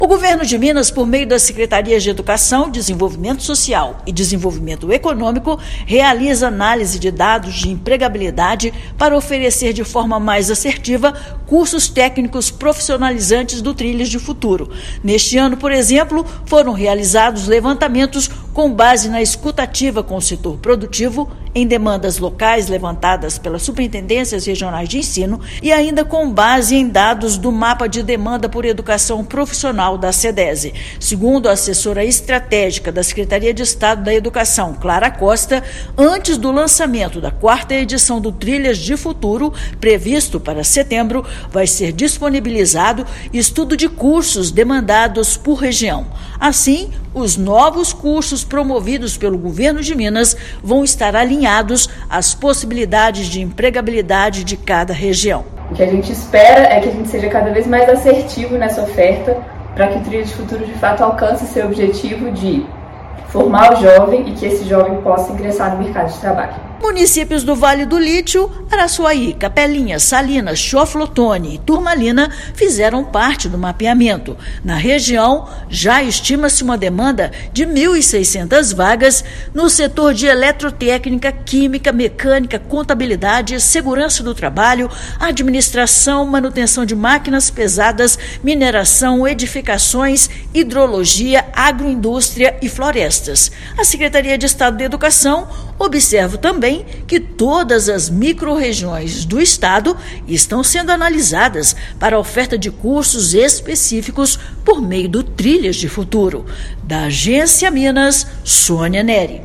Levantamento indica que há procura por mão de obra especializada em todas as regiões. A cadeia produtiva do Vale do Lítio é um exemplo, em área do estado que está em plena expansão econômica. Ouça matéria de rádio.